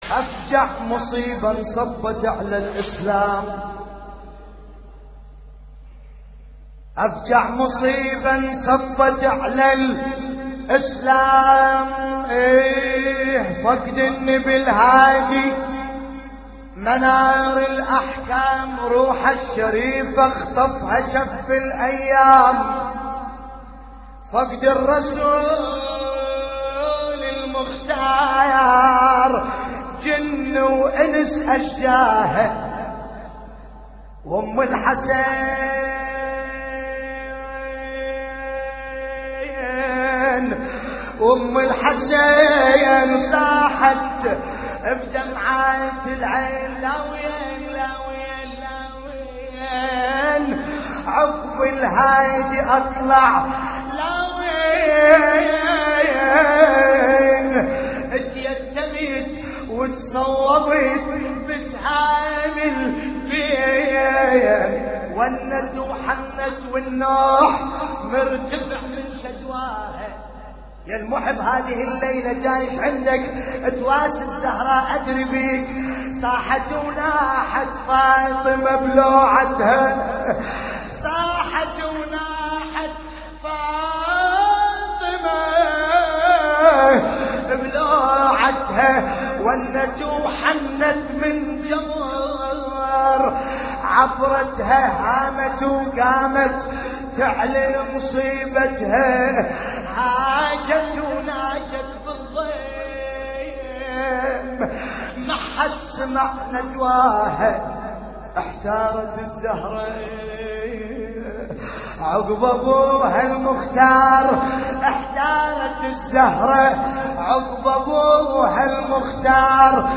نعي لحفظ الملف في مجلد خاص اضغط بالزر الأيمن هنا ثم اختر